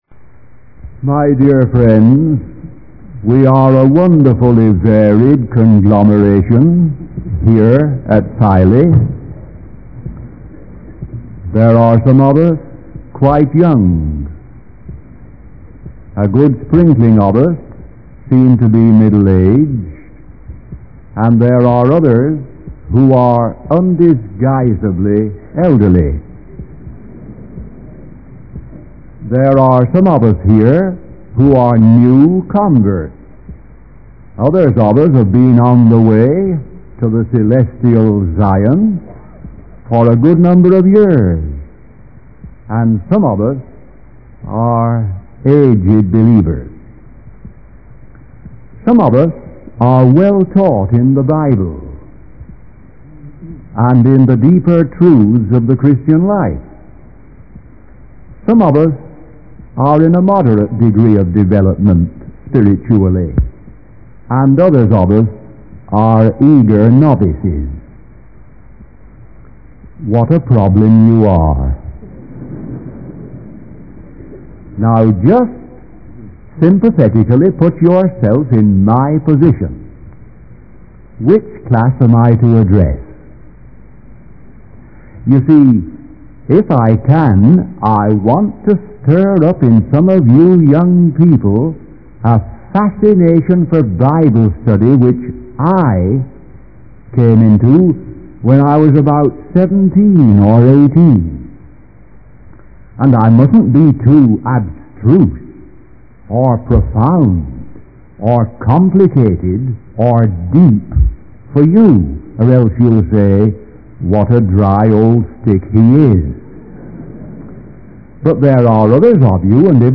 In this sermon, the speaker expresses hope for a positive change in the young people of America, as they are showing a desire for the Bible and the gospel. The speaker also emphasizes the importance of the precious blood shed on Calvary for salvation and eternal cleansing from sin.